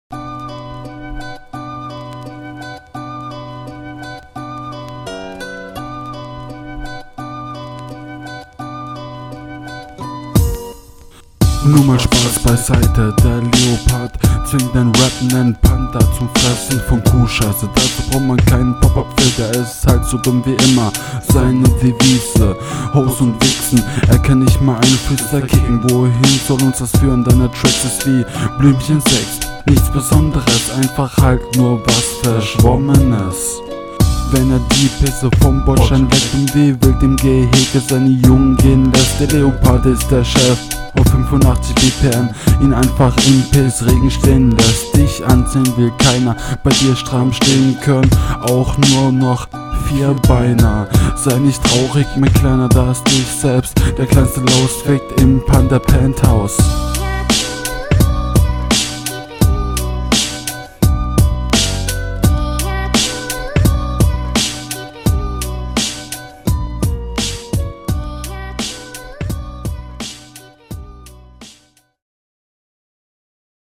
Sound ist nicht gut, er hat glaube ich nur ne Main Spur und Doubles, ich …
Ich werde hier von tiefen Frequenzen überrollt, ein zu krasser Kontakt zum Beat.